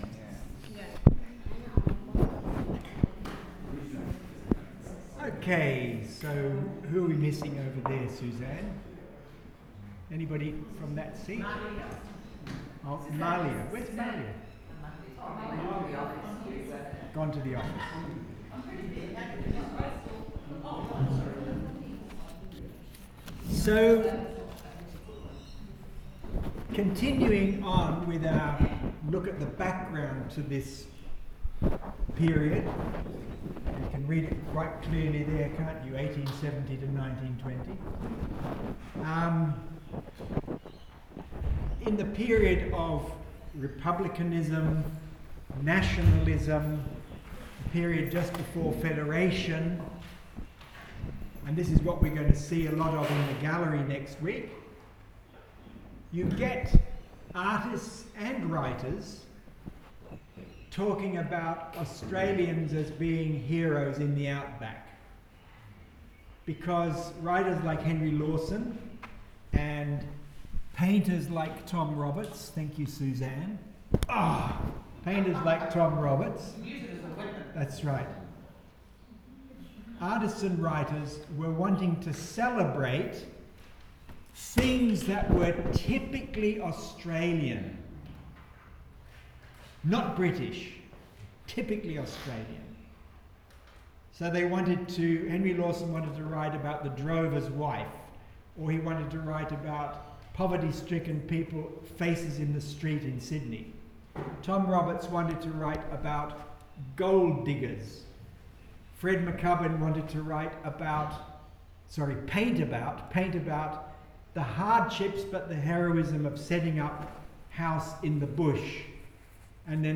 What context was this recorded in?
If these recordings are too faint, try these: